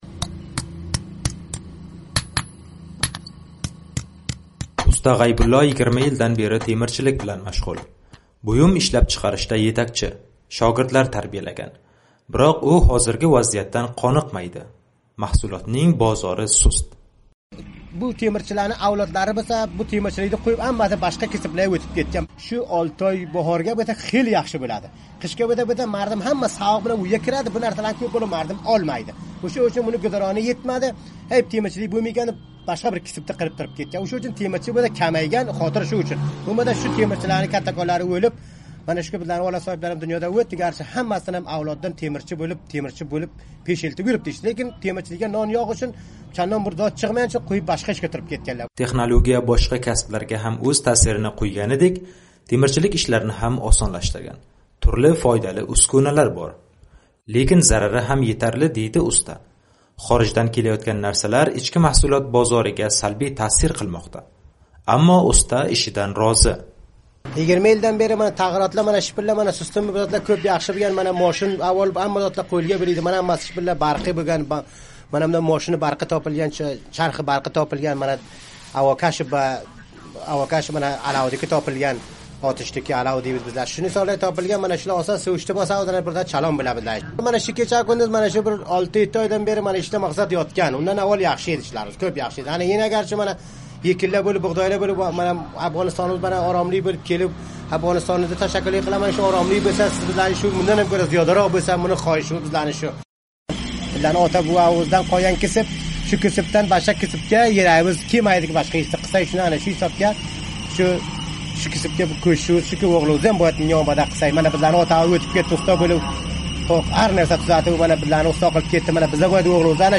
Afg'onistonda o'zbek hunarmandlari bilan suhbat